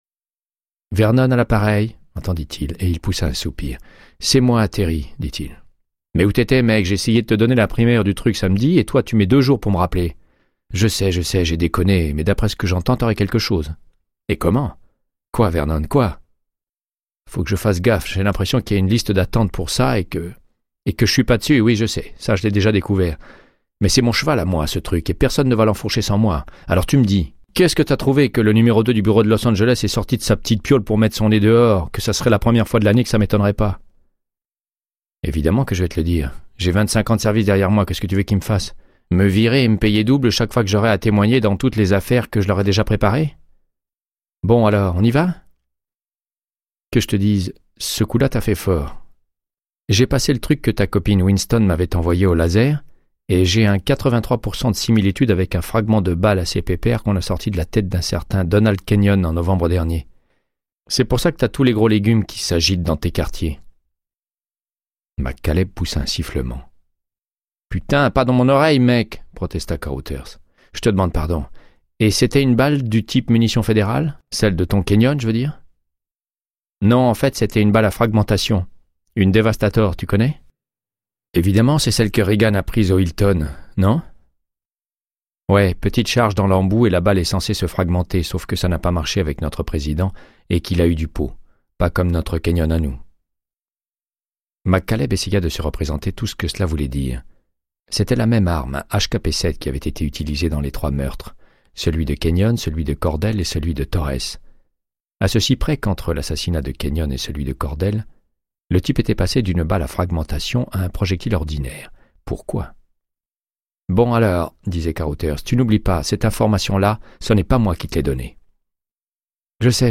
Audiobook = Créance de sang, de Michael Connelly - 97